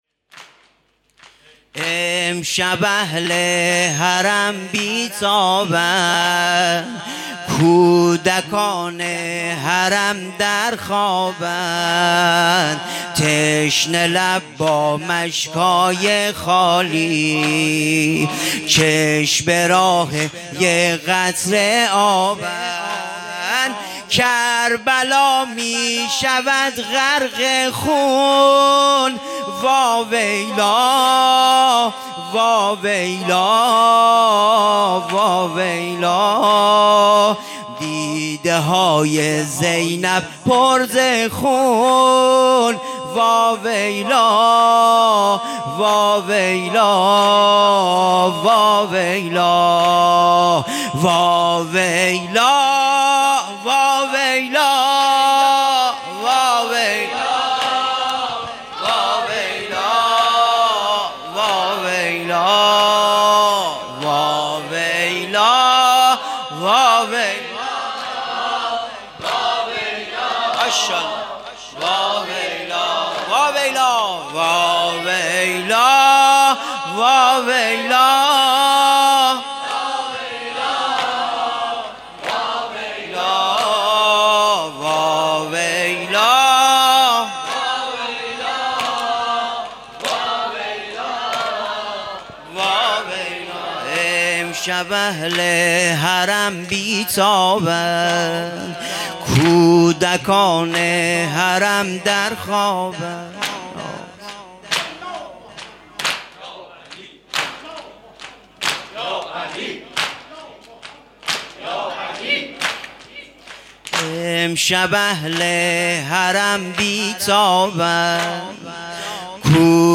ذاکر اهل بیت
نوحه سینه زنی شب عاشورا محرم ۱۴۰۱
مداحی نوحه